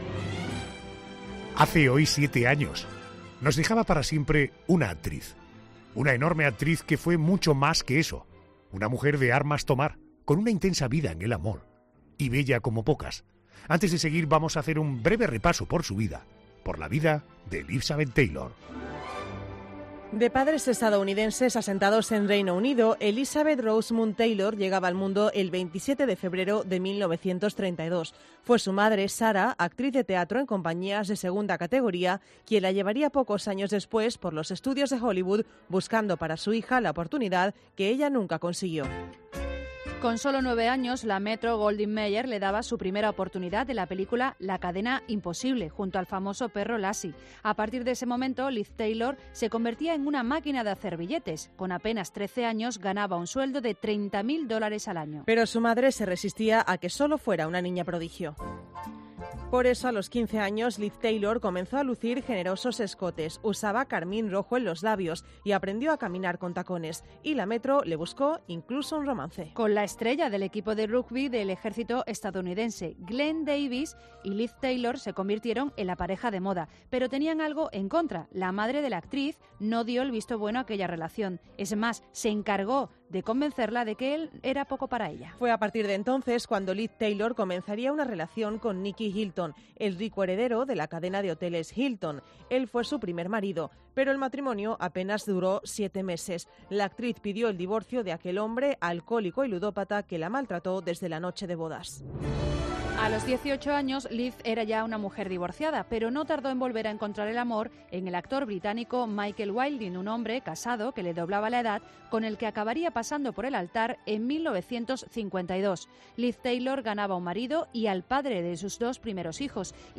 En 'La Noche de COPE'